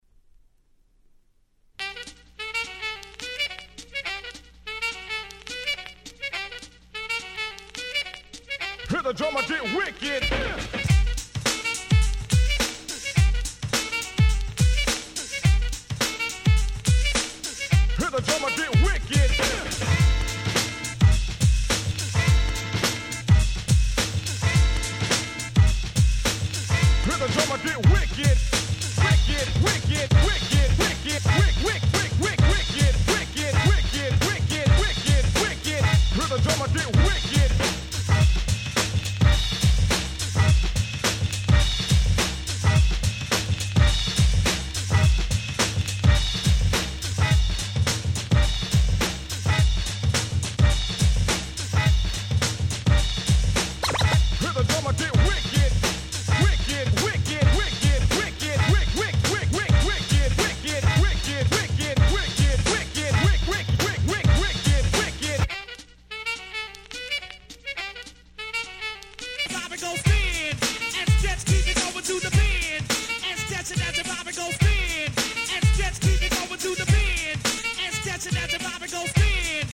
Boom Bap ブーンバップ